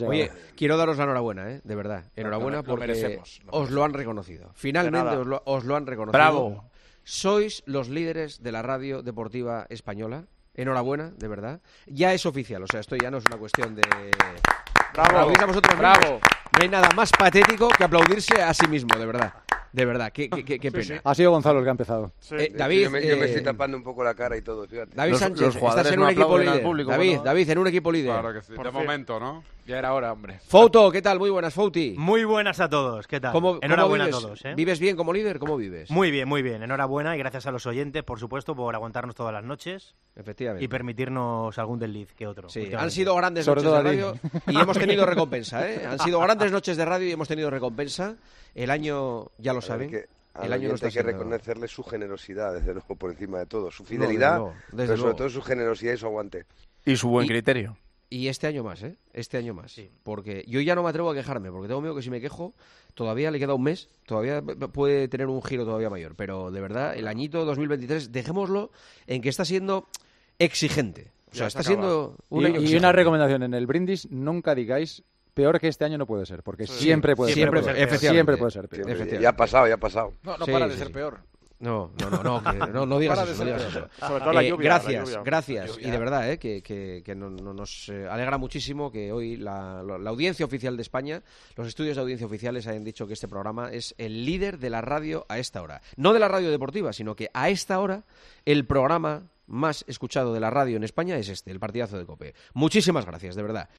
El Partidazo de COPE de este jueves no pudo comenzar de otra manera que con un mensaje de agradecimiento de Juanma Castaño a los oyentes y a su equipo habitual, por el liderazgo del programa en la tercera ola del Estudio General de Medios, conocido esta misma mañana, de 2023.
ESCUCHA EL MENSAJE DE GRACIAS DE JUANMA CASTAÑO TRAS CONOCERSE LAS CIFRAS DE LA 3ª OLA DEL EGM DE 2023